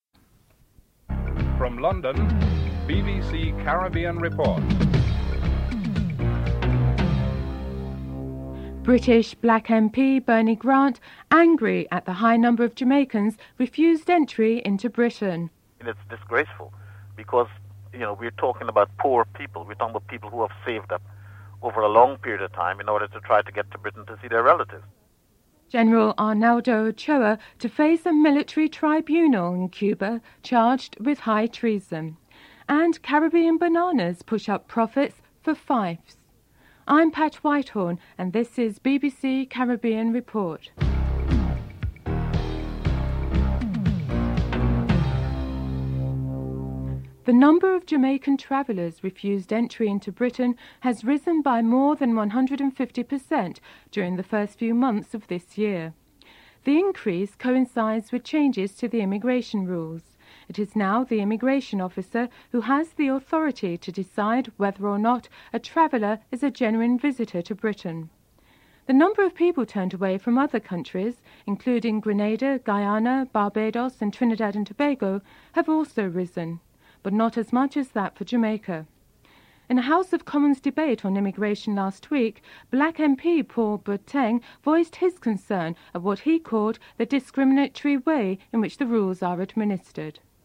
1. Headlines (00:00-00:43)